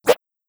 sword_swing.wav